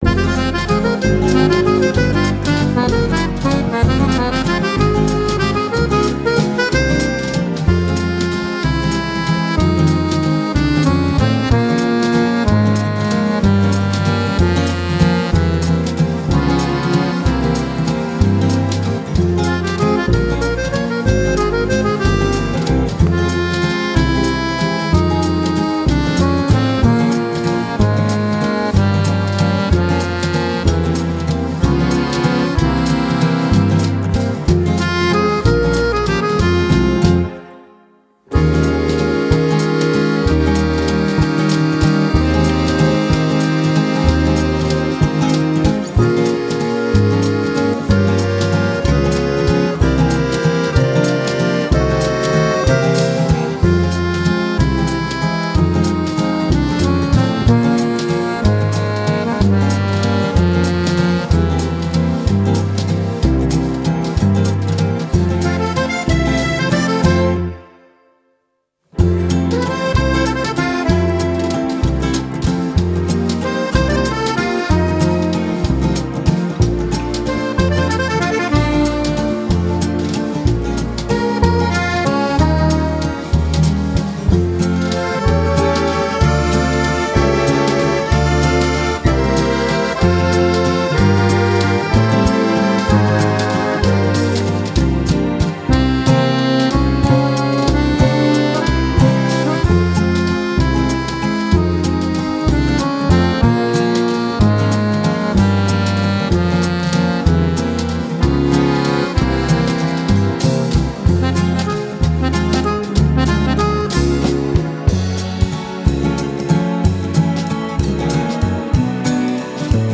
Аудио минус